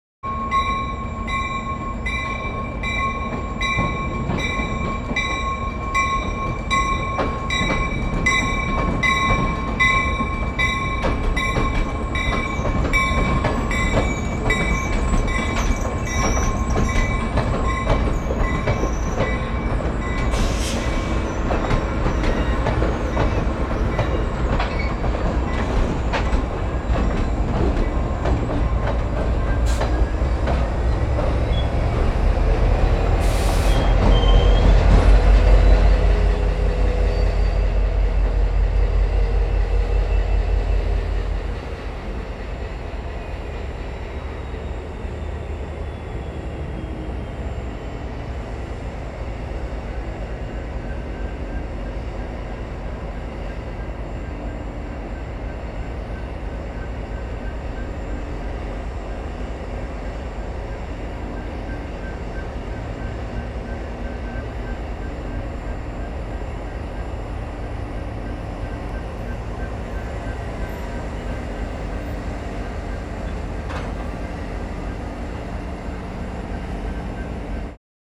Train Bell Sound
transport
Train Bell